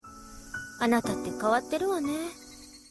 Sebagai penutup, perhatikan cuplikan dari anime CLANNAD AFTER STORY berikut: